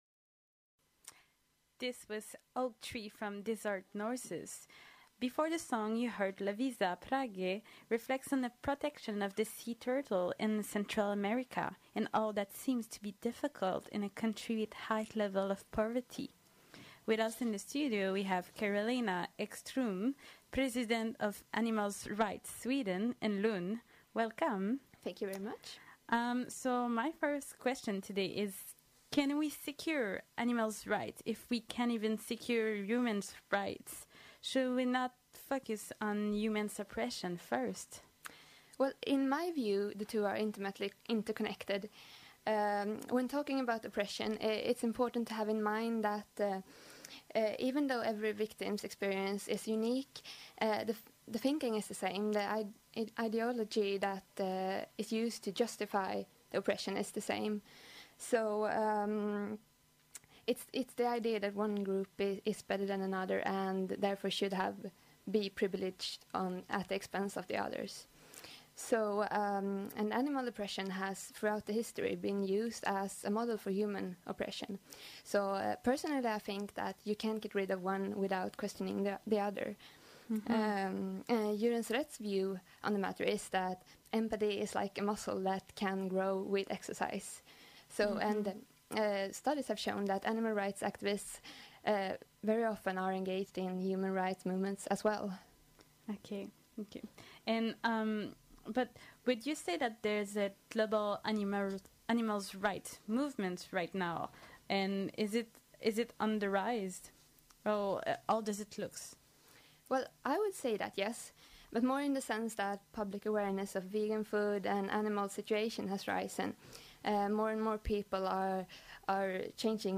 Interview
joined us in the studio to talk about the animal rights movement